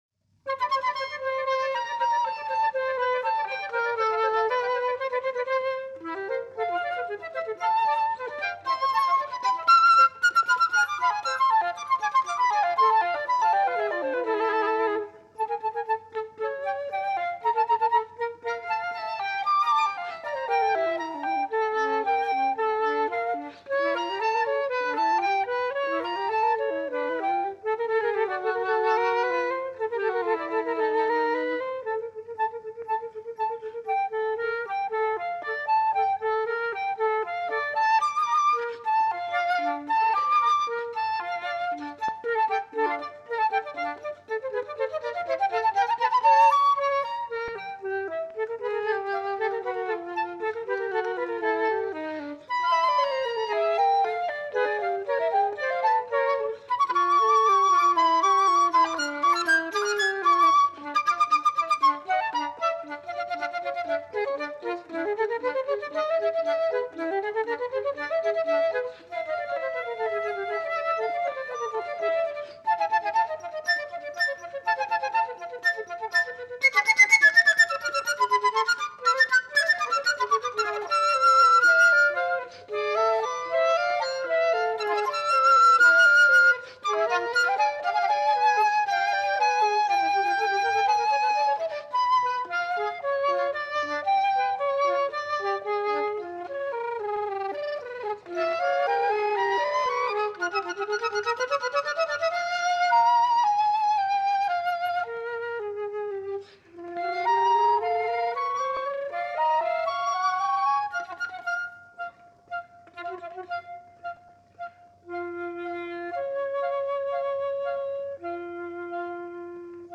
flutes
1963 broadcast recital
This broadcast recital is only one facet of her wide-ranging and highly regarded output.